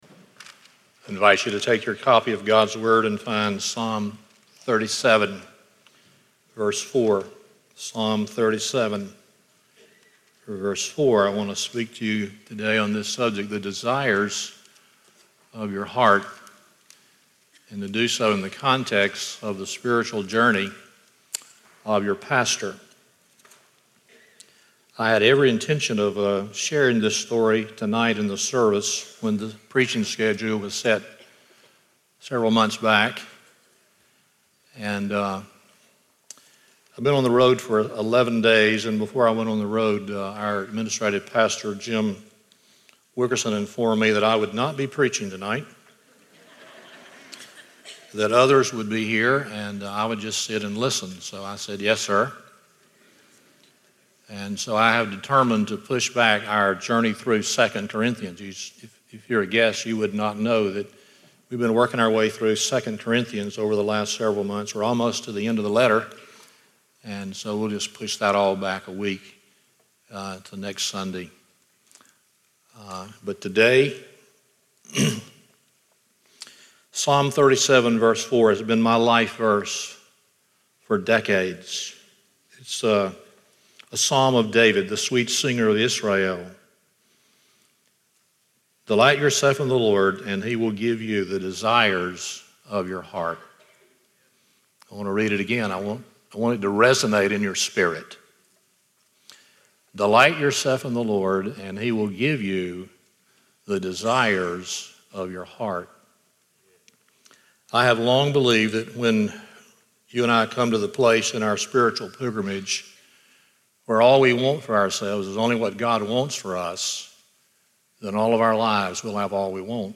Stand Alone Sermons Passage: Psalm 37:4 Service Type: Sunday Morning A personal testimony from our Pastor